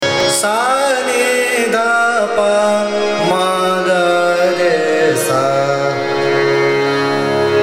The raga is characterized by its sweet and serene nature, evoking a sense of tranquility and beauty.
AvarohaS’ n D P m G R S
Khammaj (Avaroha)